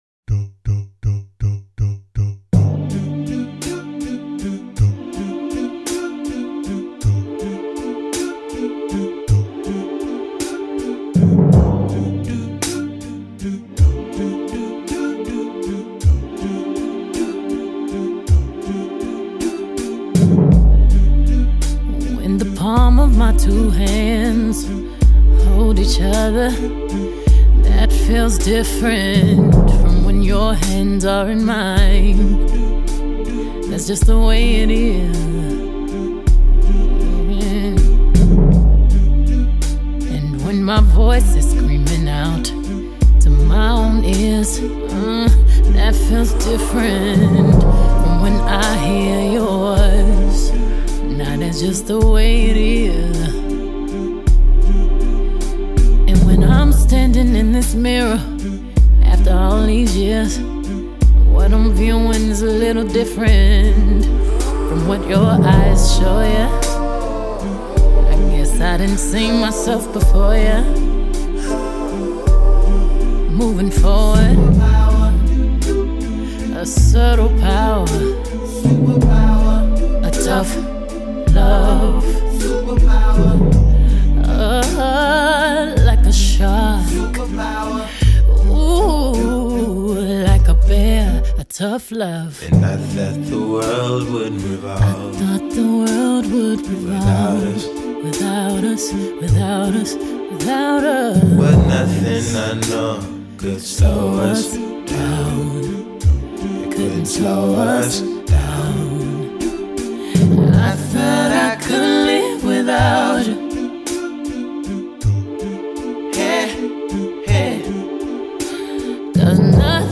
wearily sexy vocal performance